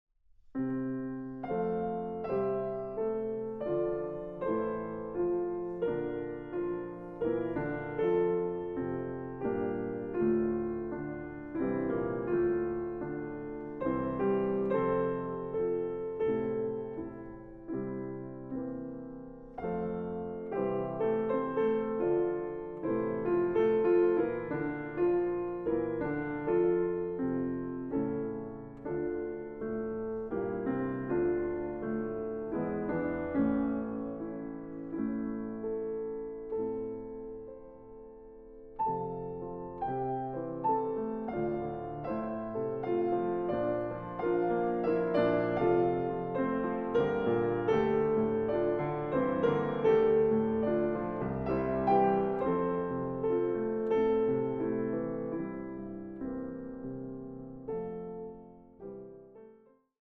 Piano
Romanze. Andante – Allegretto grazioso